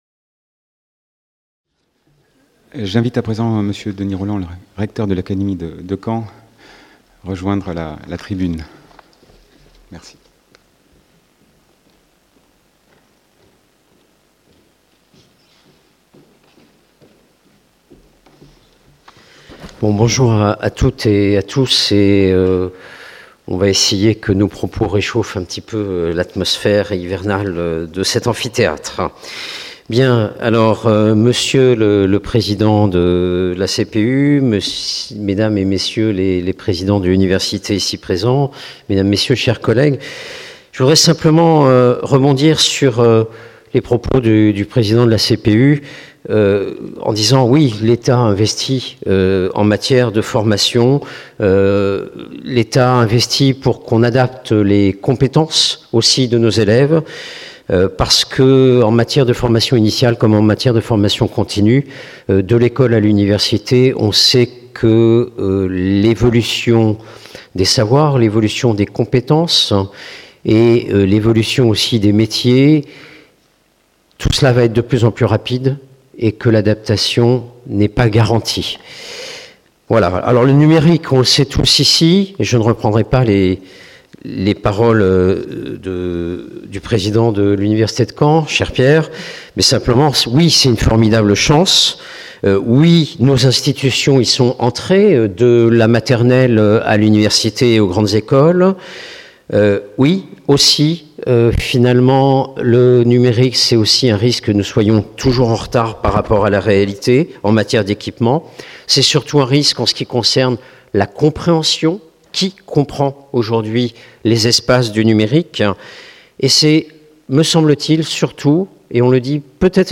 02 - Intervention de Denis Rolland, Recteur de l’Académie de Caen (cpucaen 2016) | Canal U
Colloque de La Conférence des présidents d’université (CPU) Université de Caen Normandie 30 novembre 2016 Devenir citoyen à l’ère du numérique : enjeux scientifiques et éducatifs Intervention de Denis Rolland, Recteur de l’Académie de Caen